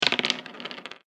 サイコロ・ダイス | 無料 BGM・効果音のフリー音源素材 | Springin’ Sound Stock
モノラル大きいダブルダイス1.mp3